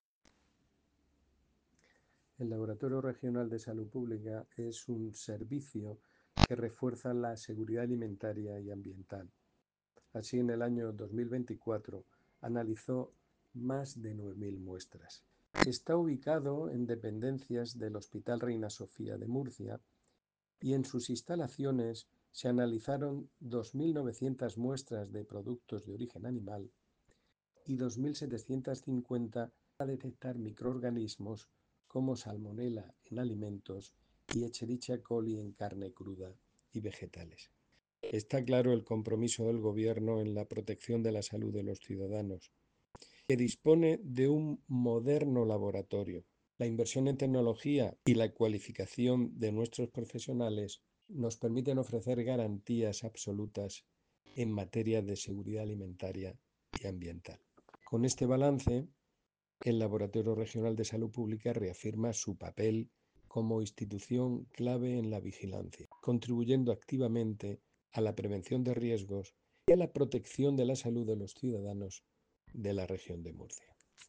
Declaraciones del director general de Salud Pública y Adicciones, José Jesús Guillén, sobre la actividad del Laboratorio Regional. [mp3]